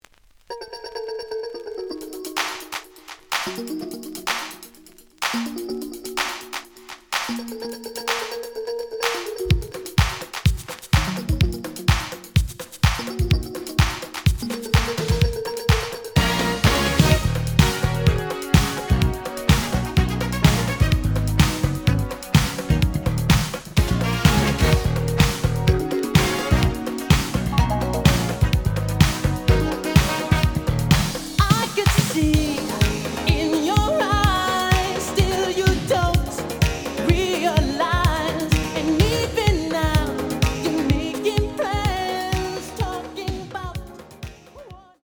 The audio sample is recorded from the actual item.
●Genre: Disco
Slight edge warp. But doesn't affect playing. Plays good.